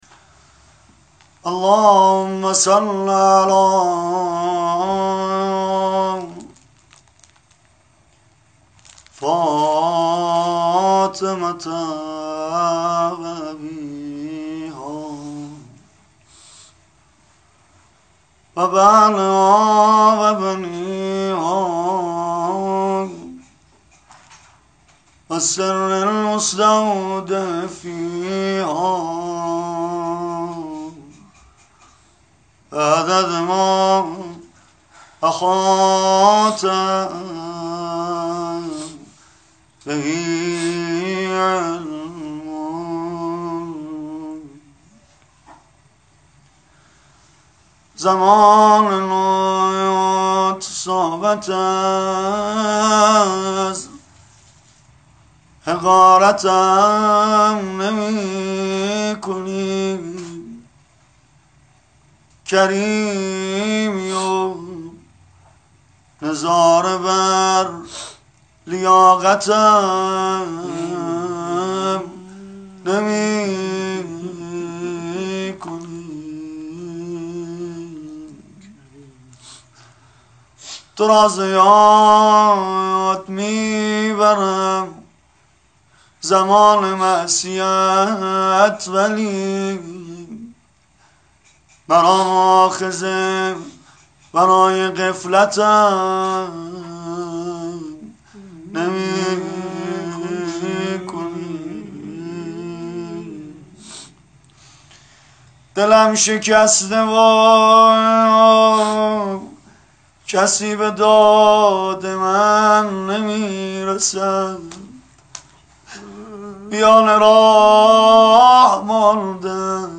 روضه خوانی سید رضا نریمانی درمنزل شهیدمدافع حرم سردار درستی
عقیق: کربلایی سید رضا نریمانی مداح اهل بیت (ع) عصر چهارشنبه با حضور در منزل خانواده شهید مدافع حرم جاوید الاثر داریوش درستی دقایقی به مداحی و روضه خوانی پرداخت.